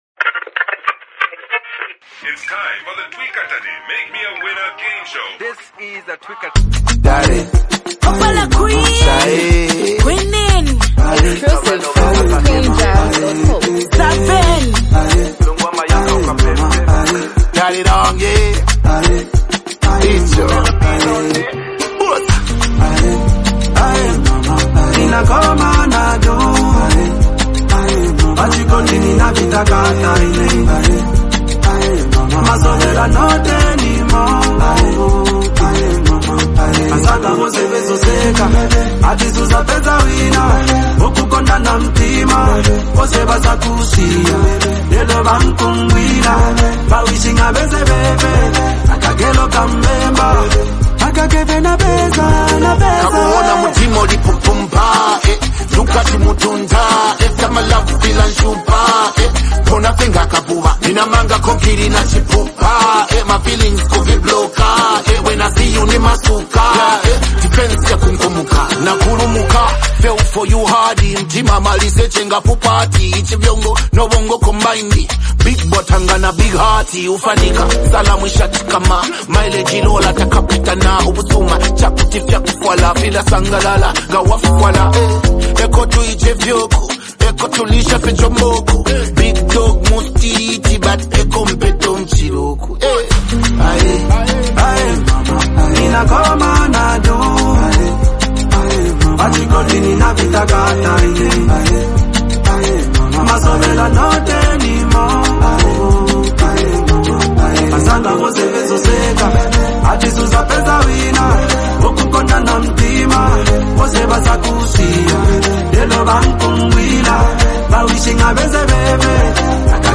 a melodic, heartfelt hook that ties the emotions together